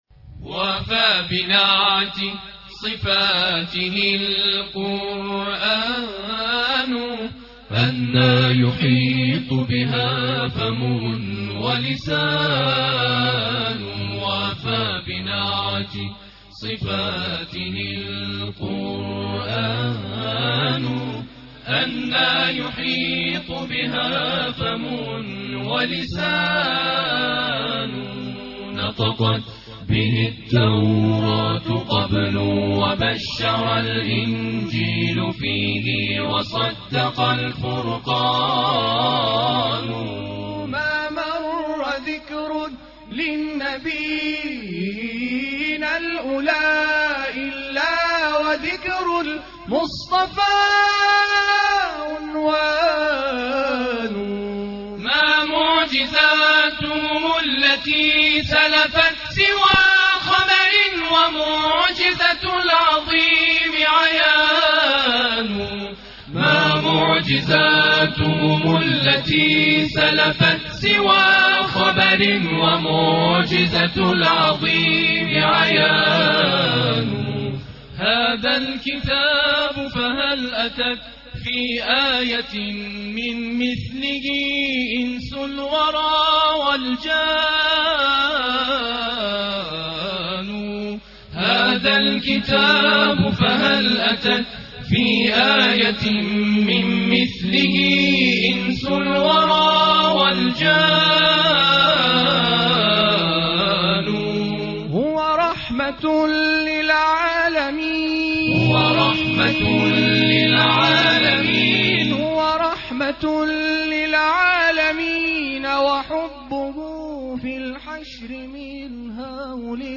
گروه فعالیت‌های قرآنی: نوزدهمین دوره مسابقات تواشیح، ابتهال و هم‌خوانی قرآن در مرحله کشوری روزگذشته برگزار شد.
بخش مدیحه سرایی
معراج- قم